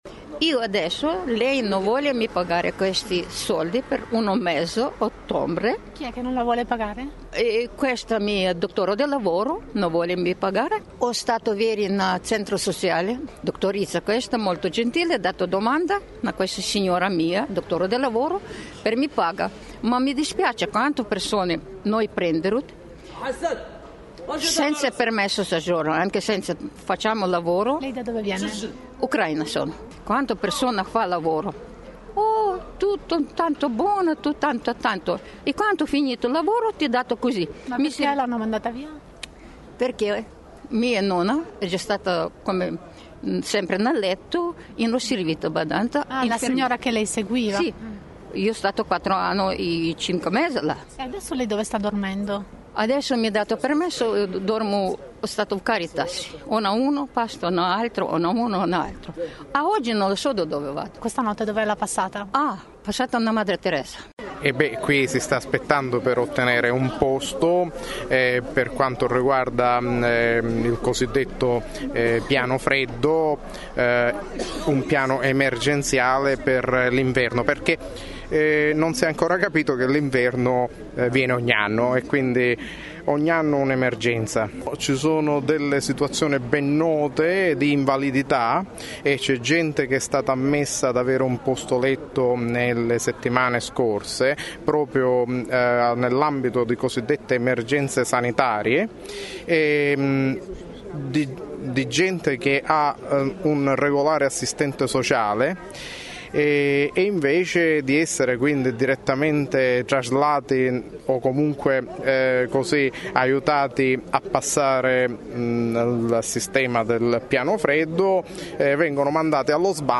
Ascolta le voci di alcune persone in coda in via del Porto